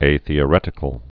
(āthē-ə-rĕtĭ-kəl)